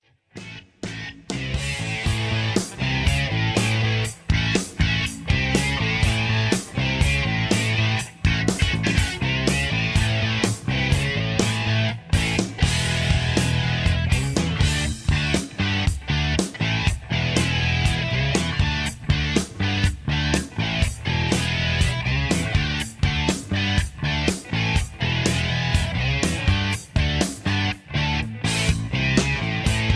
Tags: karaoke, backing tracks, rock and roll